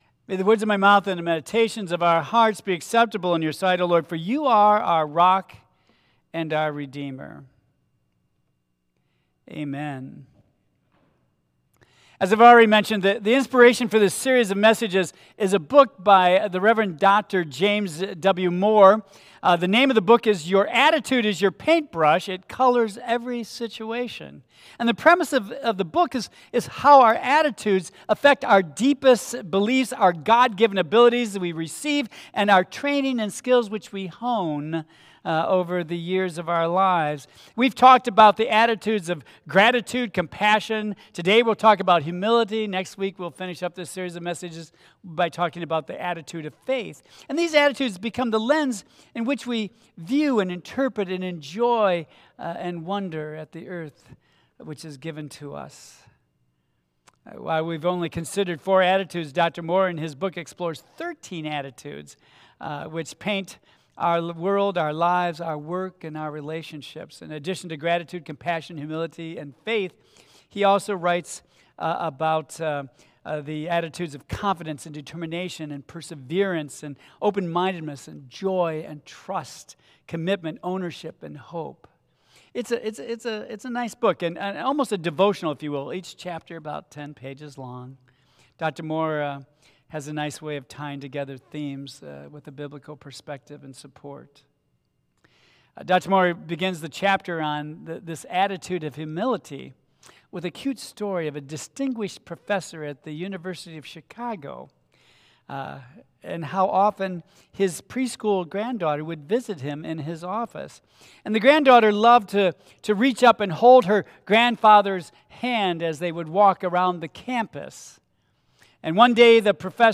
Tagged with Michigan , Sermon , Waterford Central United Methodist Church , Worship Audio (MP3) 37 MB Previous The Bread of Life Next The Attitude of Faith